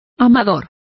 Also find out how amadores is pronounced correctly.